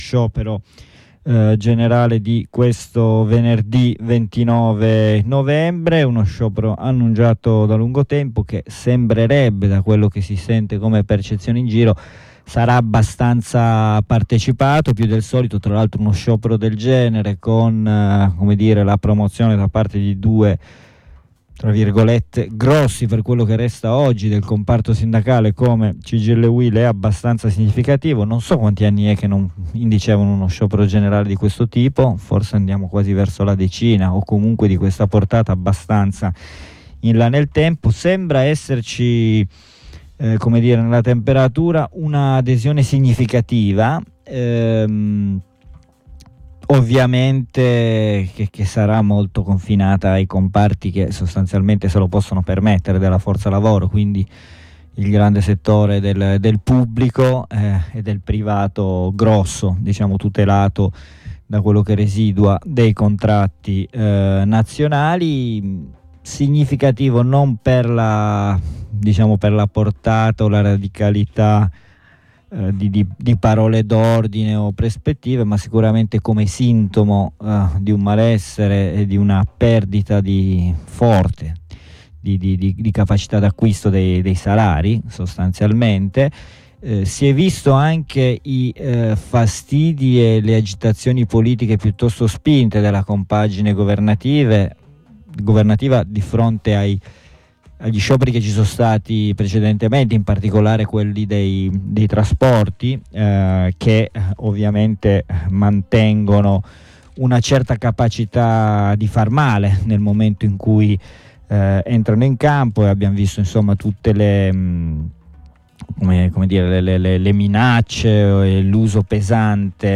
Uno sciopero che risponde, certamente con ritardo ma comunque sintomo importante di una situazione non più a lungo sostenibile, alla necessità di rimettere al centro la questione salariale in un paese – eccezione in Europa – che ha visto diminuire, in maniera tendenziale e via via sempre più accelerata, il valore reale dei salari di fronte all’innalzamento generale dell’inflazione. Vi proponiamo la lettura di 2 articoli usciti lunedì’ 27 novembre sul Fatto Quotidiano